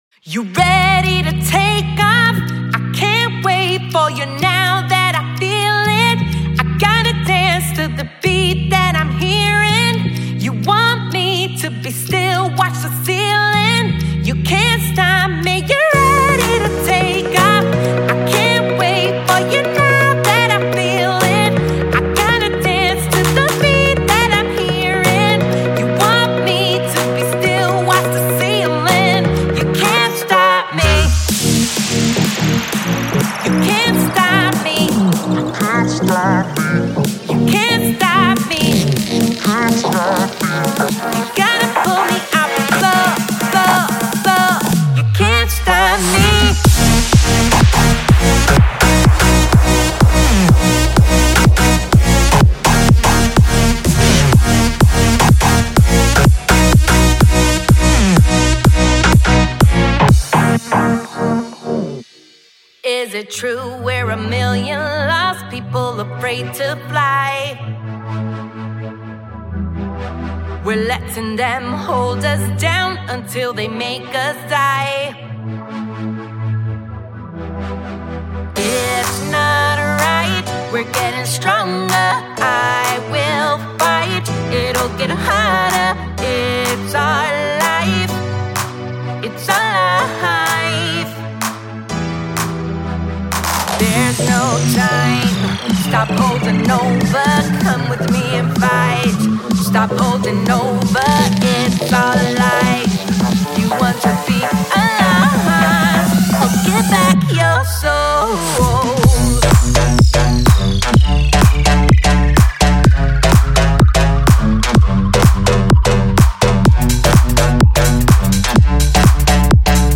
4. Future House
在这个惊人的包中，您会发现准备发出隆隆声的原始人声。
所有人声均处于干音和湿音模式，并提供相应的MIDI文件。
•123-126 BPM
•12个干燥的人声短语
•4个湿的人声短语